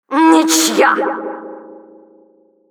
Администратор про Hydro
Announcer_stalemate_ru.wav